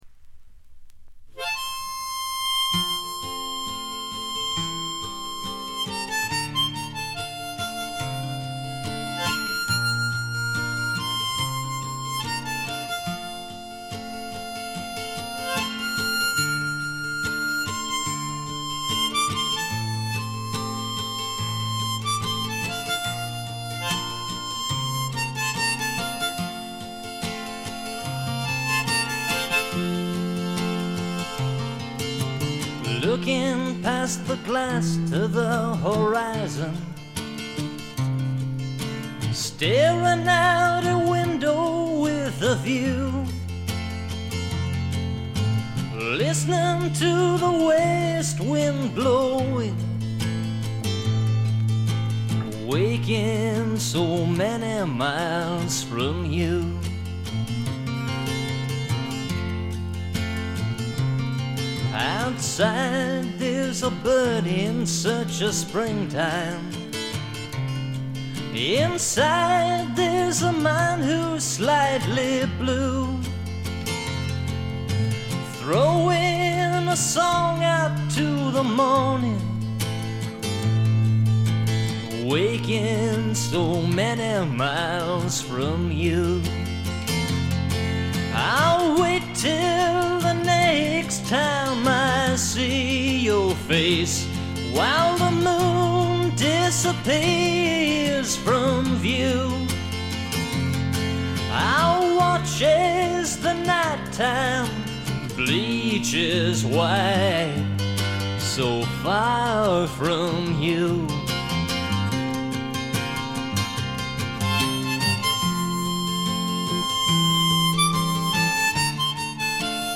気付いたのはこれぐらいで、ほとんどノイズ感無し。
試聴曲は現品からの取り込み音源です。